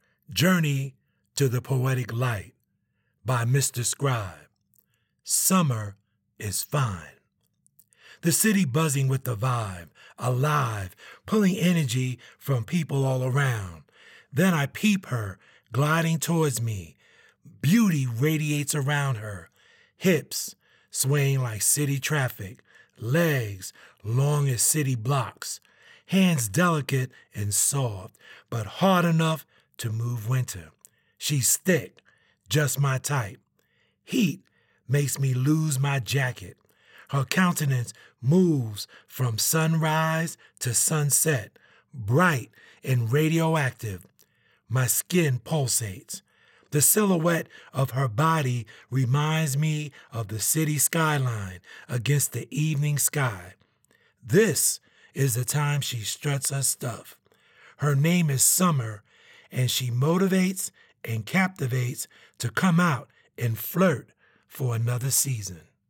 Summer is Fine" a poetry piece